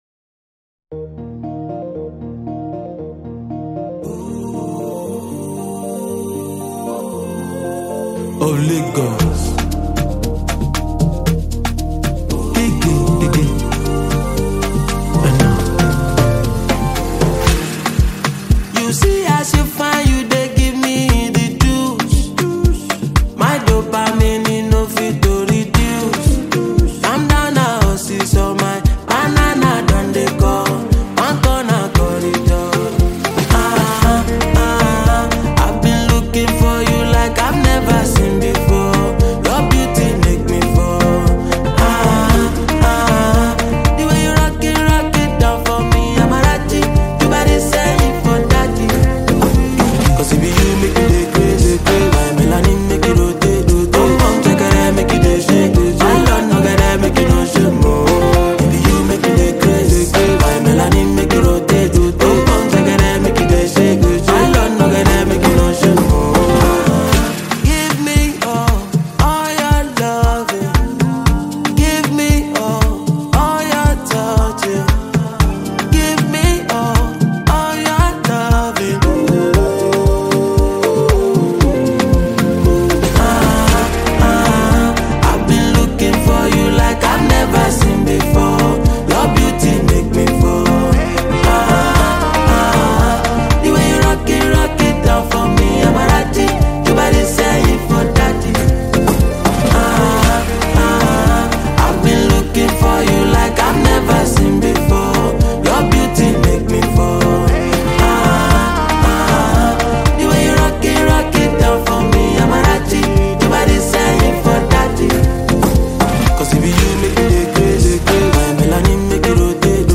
Nigerian Afro-pop music star